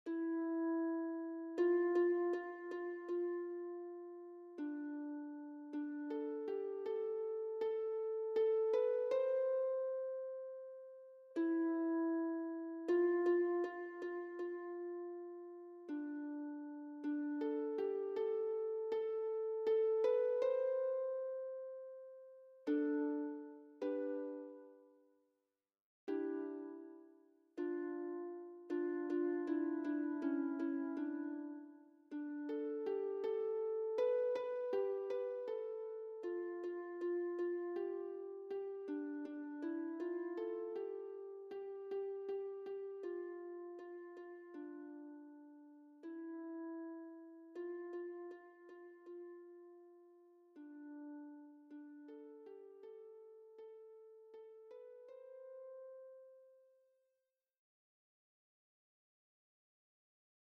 non spécifié (3 voices mixed OR equal) ; Full score.
Sacred.
Soloist(s): Célébrant (1) (1 soloist(s))
Tonality: C major